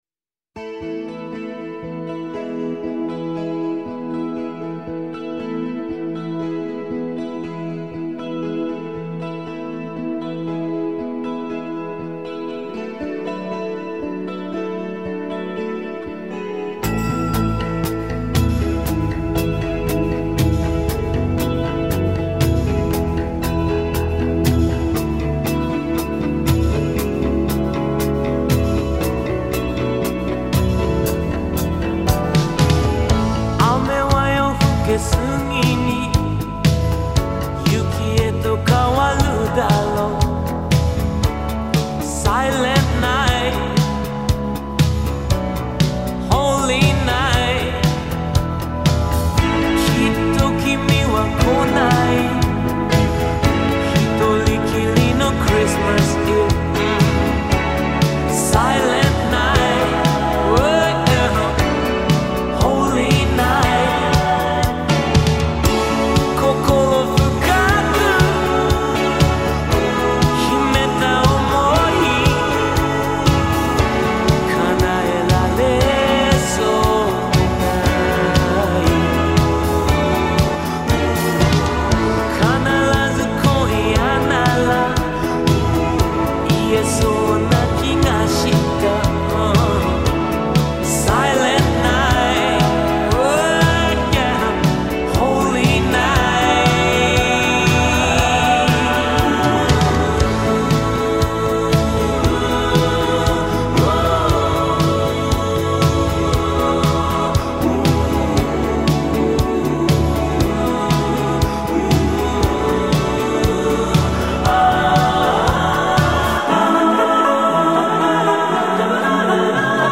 중간에 캐논 멜로디가 묘하게 어울리네요